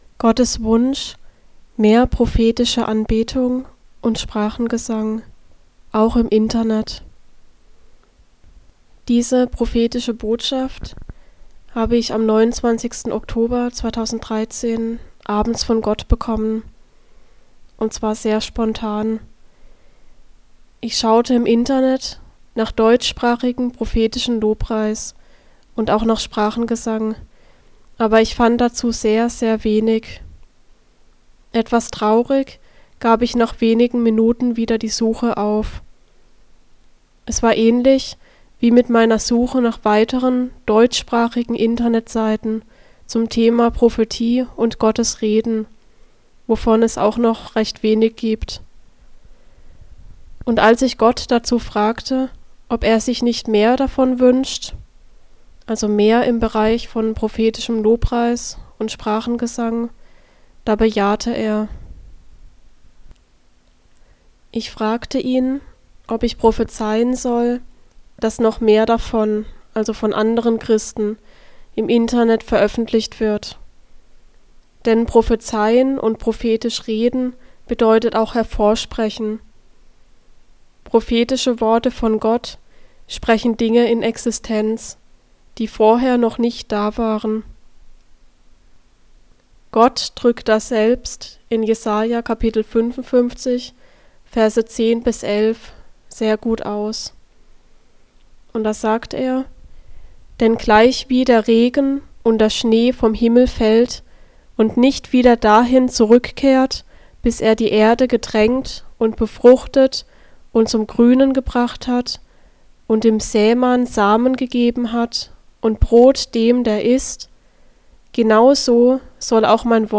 (In der Audiodatei bete ich am Ende, ab ca. 16:25 Minuten, für die Zuhörer.